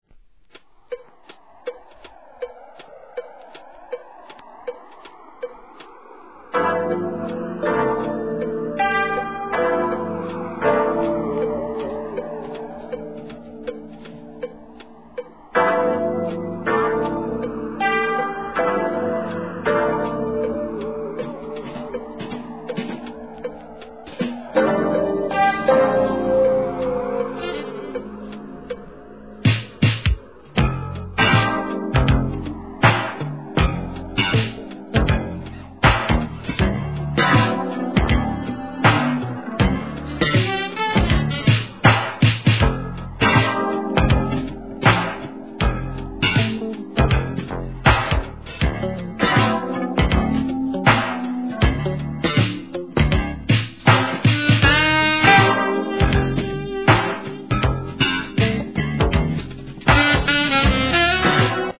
店舗 ただいま品切れ中です お気に入りに追加 アーバンでロマンチックなALTO SAX! JAZZ FUSION.